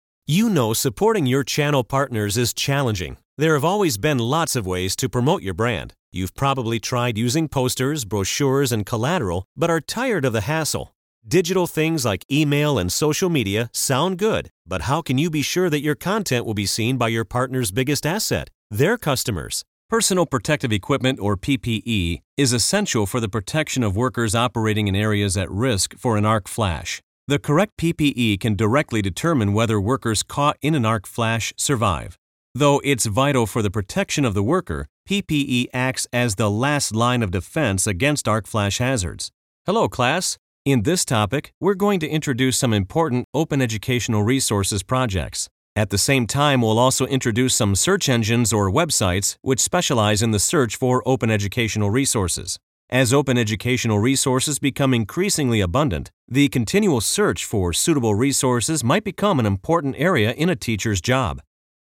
middle west
Sprechprobe: eLearning (Muttersprache):